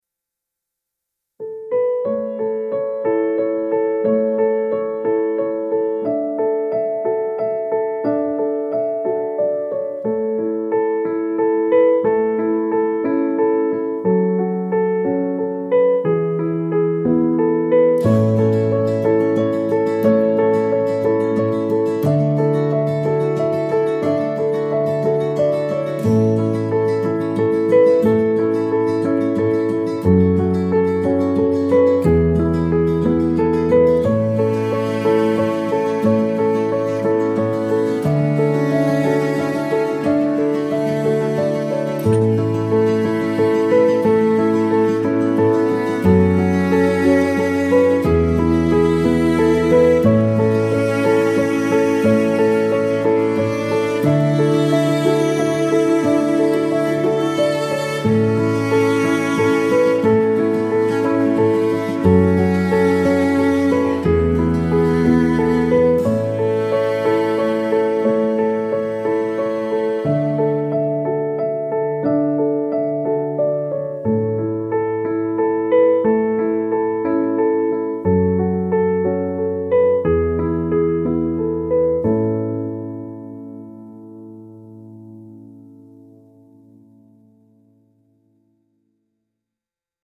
loving gentle melody evoking togetherness and cherished family bonds